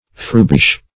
Frubish \Frub"ish\